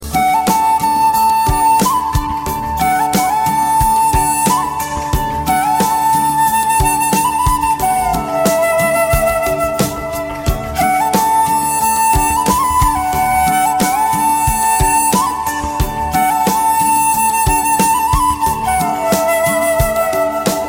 Hindi Ringtones